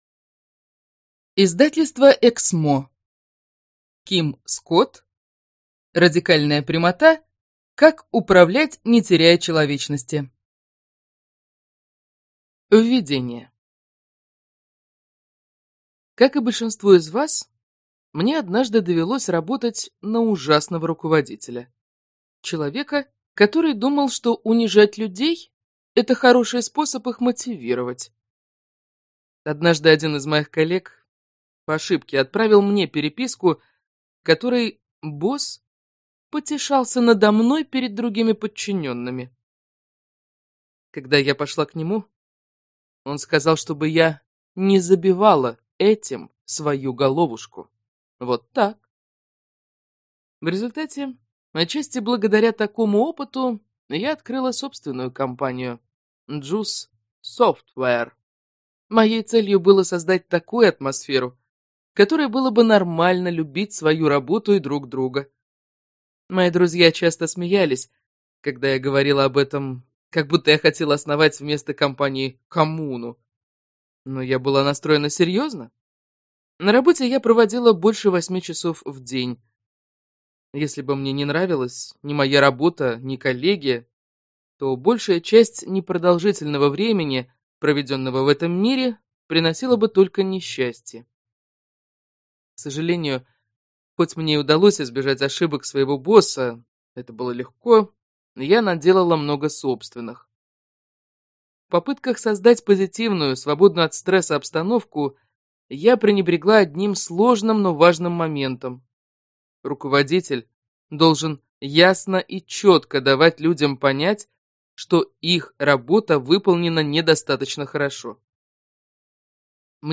Аудиокнига Радикальная прямота. Как управлять людьми, не теряя человечности | Библиотека аудиокниг